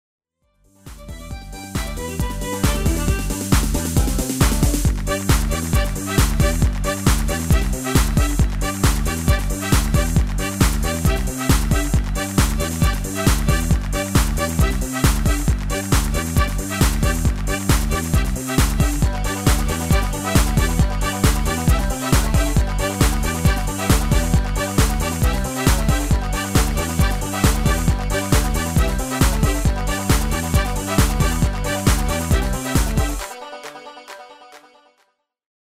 Demo/Koop midifile
Genre: Country & Western
- Géén vocal harmony tracks
Demo = Demo midifile